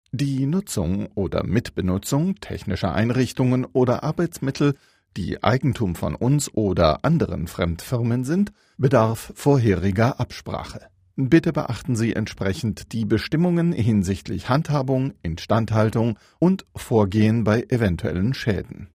Deutscher Sprecher, Werbesprecher für Einkaufsparks und Center, Telfonjingles, Profischauspieler, Hörbücher, Hörspiele, Produzent, all inklusive, Overvoice, Voiceover, Dokfilm, Reportagen, Heimatfilm, Kommentar, Kurzfilm, Offsprecher, Synchron, Spielfilm, Dokumentation,
Sprechprobe: eLearning (Muttersprache):